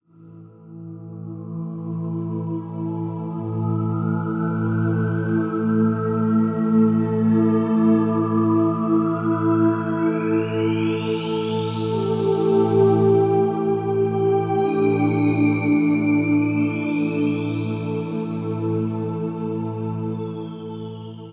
标签： 90 bpm Cinematic Loops Harp Loops 3.59 MB wav Key : Unknown
声道立体声